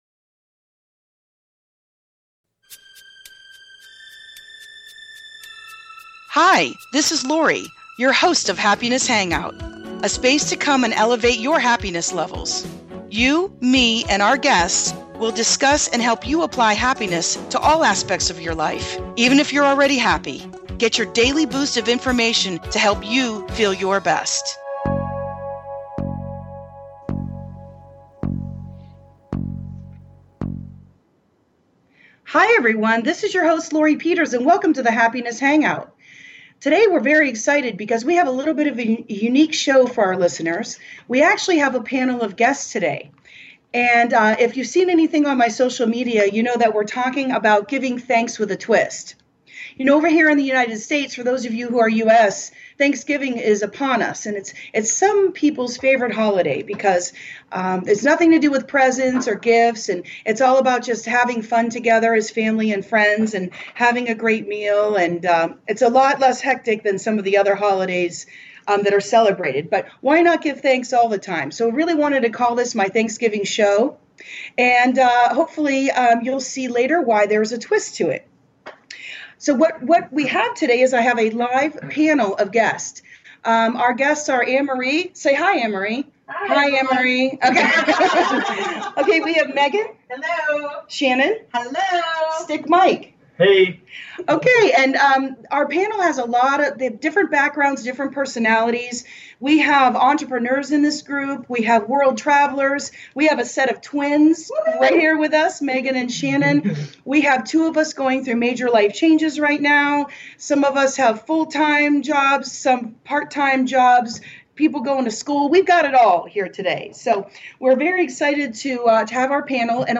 My guests are relationships experts, life coaches, therapists and much more. We discuss all aspects of how to create and keep a healthy relationship.